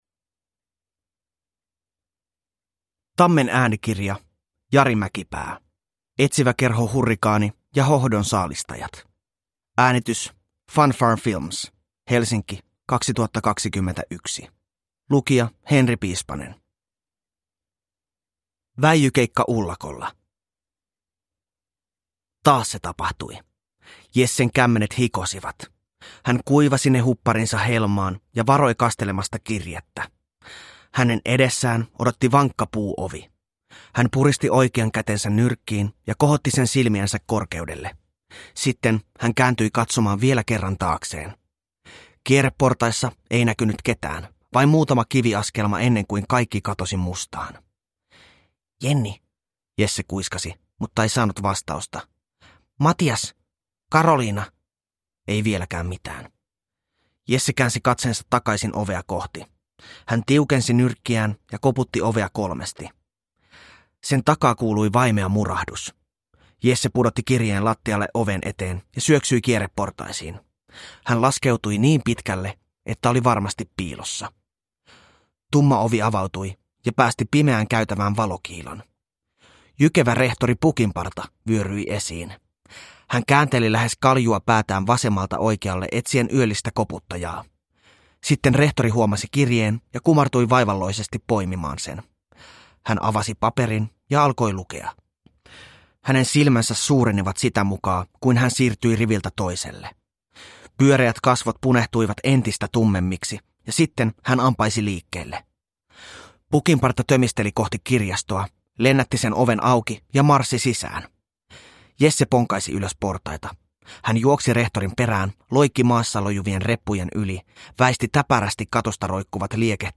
Etsiväkerho Hurrikaani ja hohdon saalistajat – Ljudbok – Laddas ner